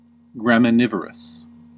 Ääntäminen
Ääntäminen US Tuntematon aksentti: IPA : /ɡɹæmɪˈnɪvəɹəs/ Haettu sana löytyi näillä lähdekielillä: englanti Käännöksiä ei löytynyt valitulle kohdekielelle.